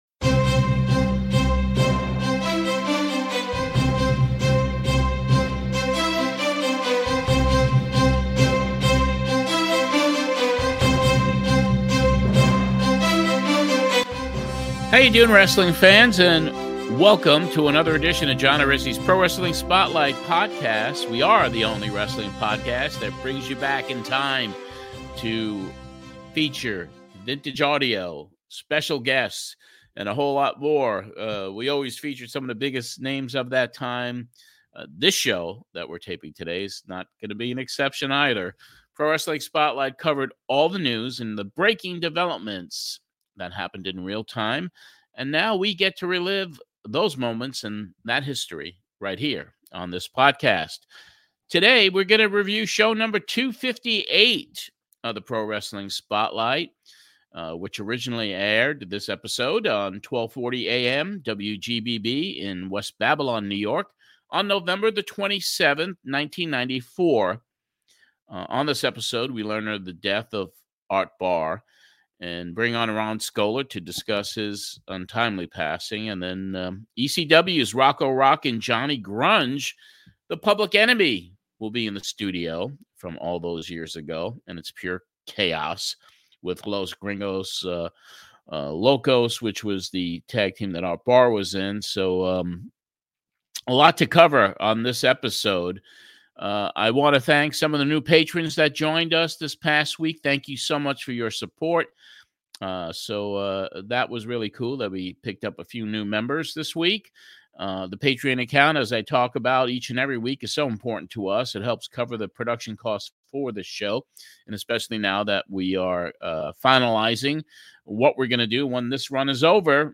Here is episode 104 of the Pro Wrestling Spotlight Podcast, covering the original broadcast of of show #258, which originally aired on 1240 AM WGBB in West Babylon, NY on November 27th, 1993.